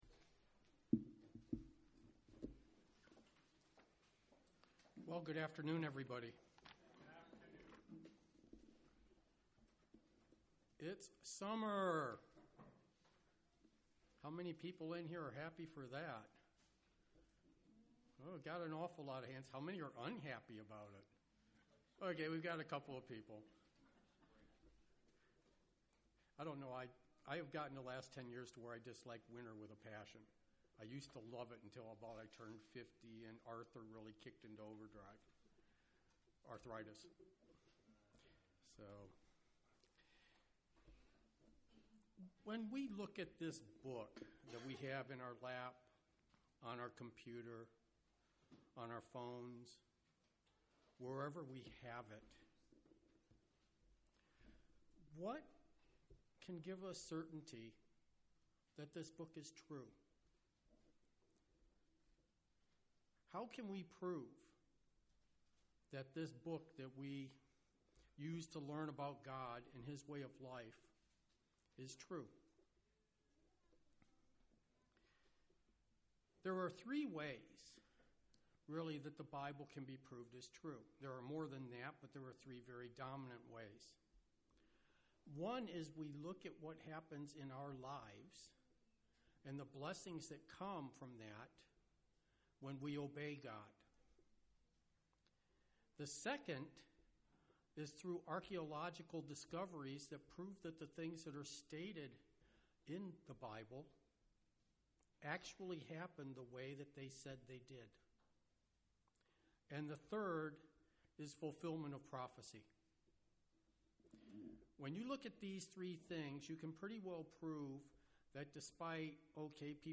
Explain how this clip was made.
Proving topics found in The Bible such as tithing principles and real accounts through archaeological findings and financial advice. The first 5 mins are quiet.